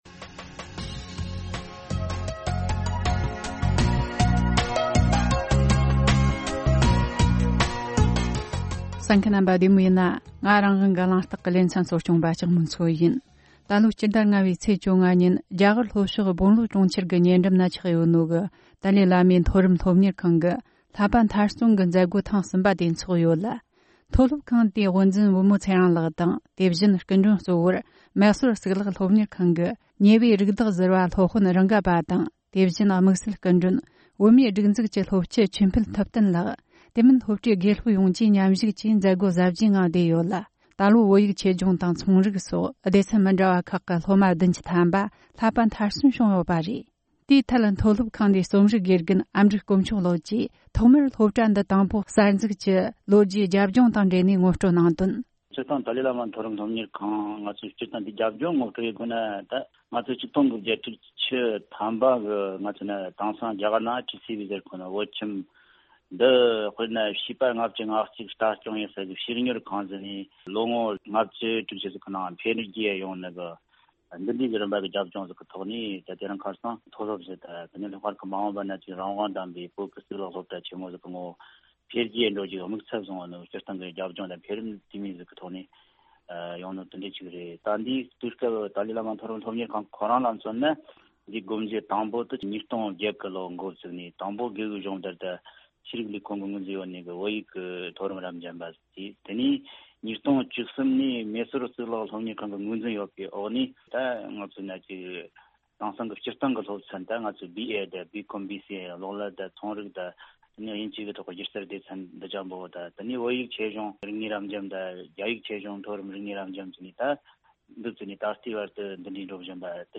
གླེང་མོལ་བྱས་བར་གསན་རོགས་གནོངས།།